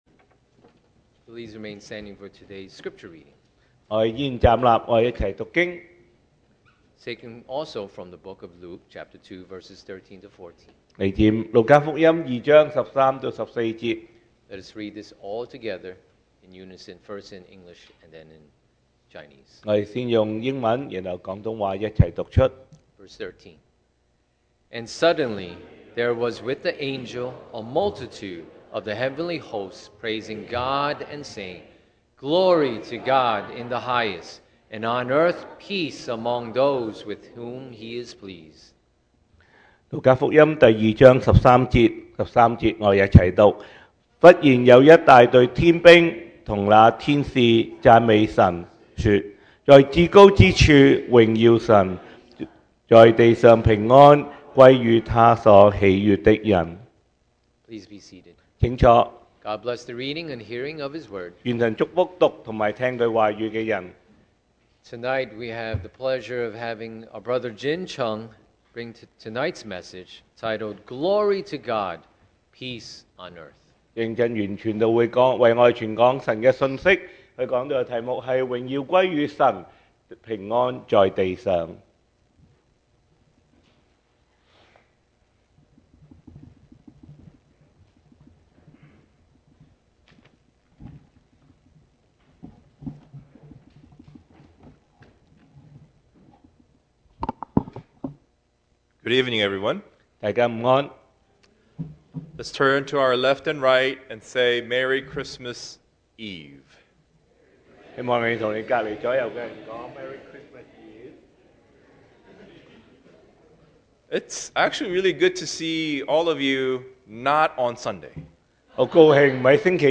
2024 sermon audios
Service Type: Christmas' Eve